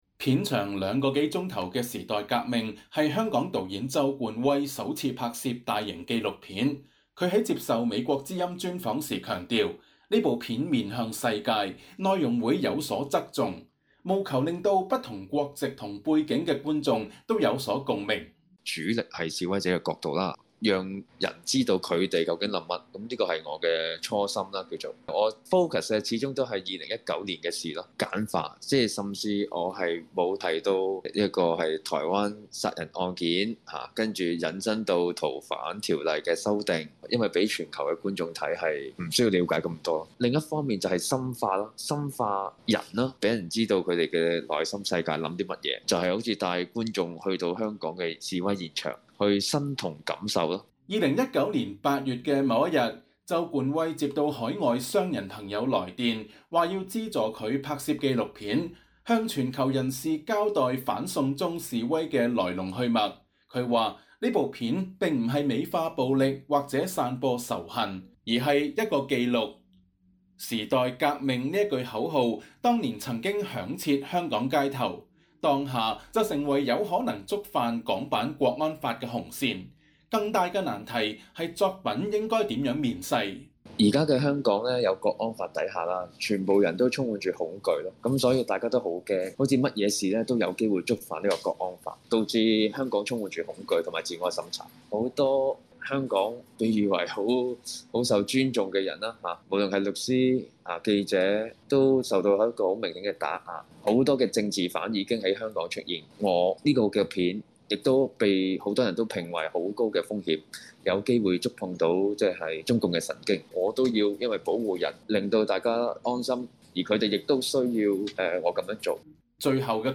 他在接受美國之音專訪時表示，這部片子使他重新認識自我。雖然自知有可能淪為政治犯，但他決意留在香港，他認為只有這樣才能戰勝內心的恐懼。